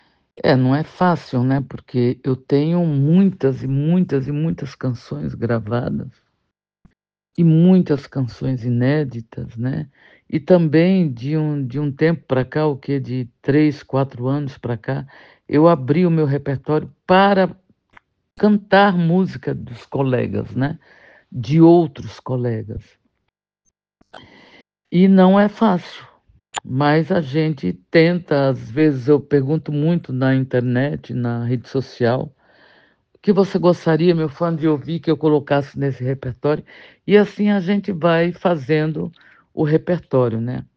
“Não abro mão da poesia, da história, boa música e da arte” diz Roberta Miranda em entrevista exclusiva para o Curitiba Cult; cantora faz show na cidade em agosto